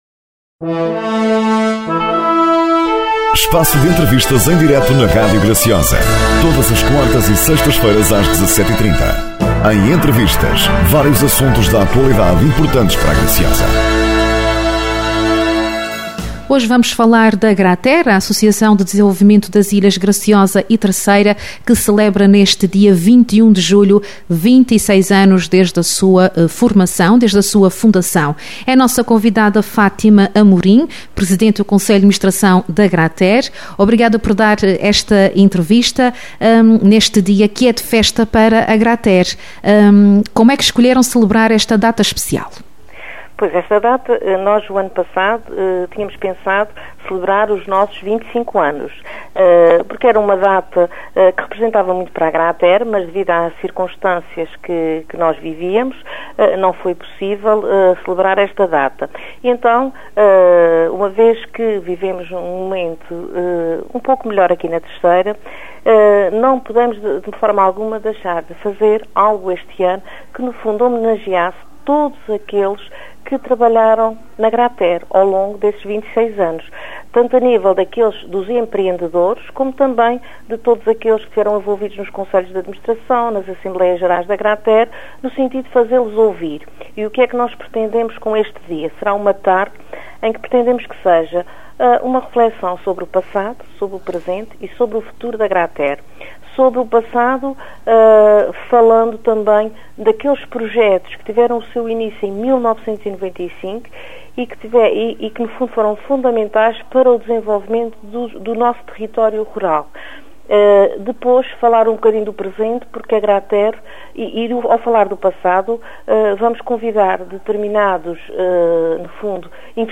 Entrevistas
entrevistas_quarta.mp3